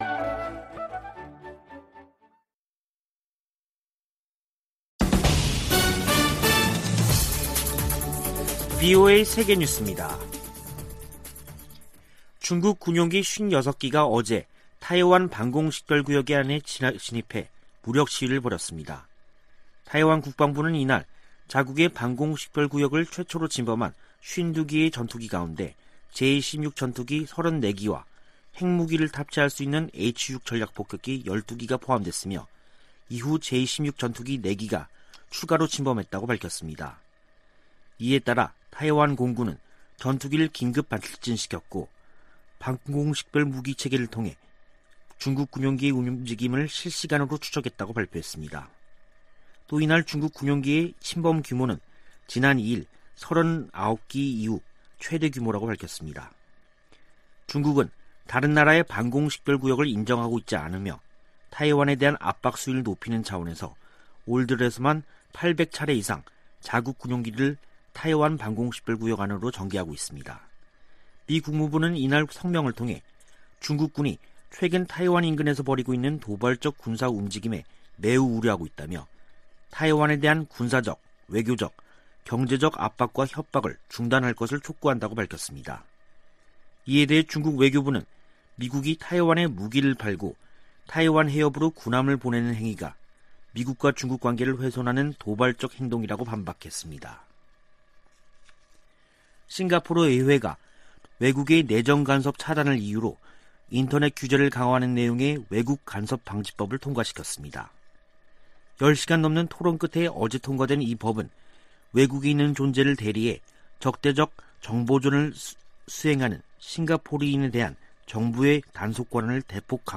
VOA 한국어 간판 뉴스 프로그램 '뉴스 투데이', 2021년 10월 5일 2부 방송입니다. 유엔 안보리 전문가패널이 북한의 제재 위반 사례를 담은 보고서를 공개했습니다. 미 국무부는 북한이 안보리 긴급회의 소집을 비난한 데 대해 유엔 대북제재의 완전한 이행 필요성을 강조했습니다. 문재인 한국 대통령은 남북한 체재경쟁이나 국력 비교는 의미 없어진 지 오래라며 협력 의지를 밝혔습니다.